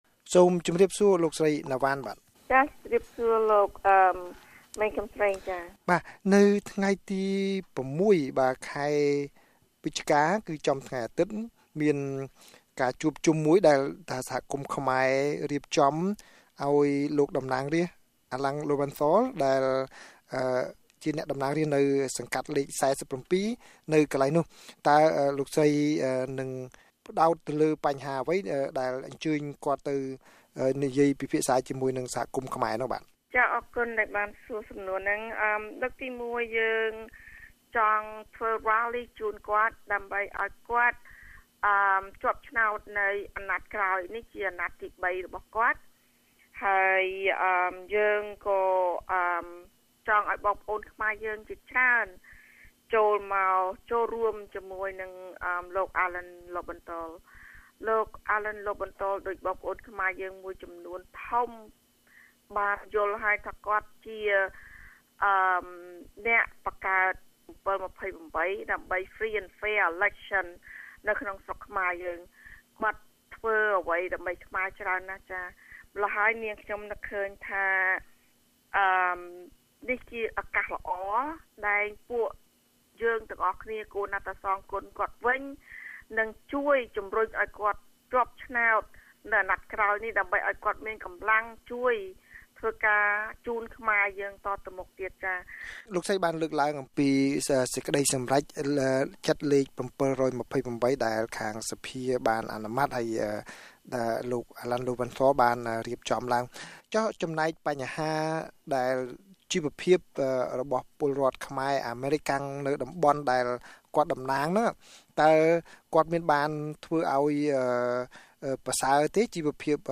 បទសម្ភាសន៍ VOA៖ សកម្មជនរៀបចំវេទិកាសាធារណៈដើម្បីជំរុញខ្មែរនៅក្រុងឡុងប៊ិចទៅបោះឆ្នោត